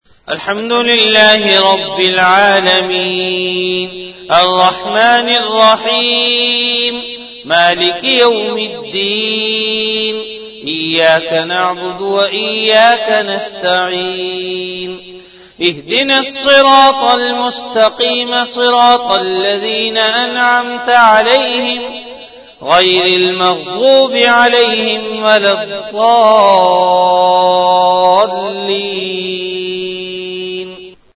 CategoryTilawat
VenueJamia Masjid Bait-ul-Mukkaram, Karachi
Event / TimeAfter Isha Prayer